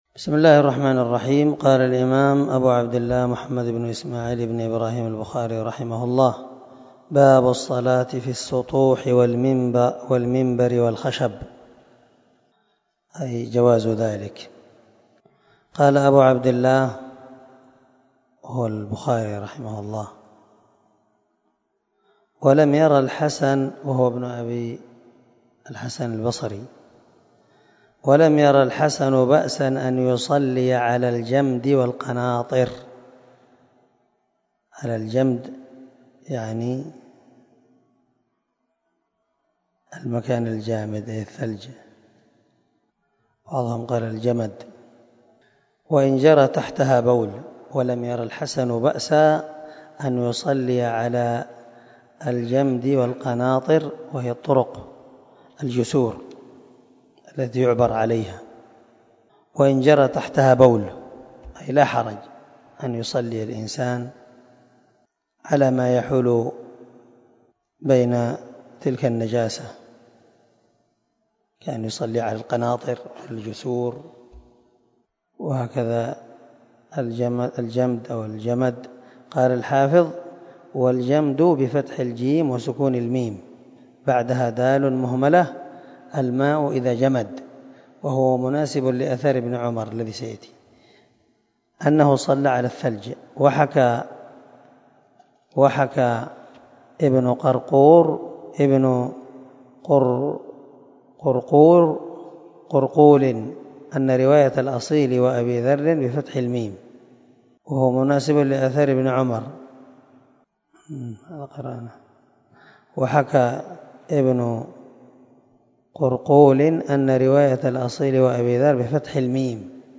290الدرس 23 من شرح كتاب الصلاة حديث رقم ( 377 ) من صحيح البخاري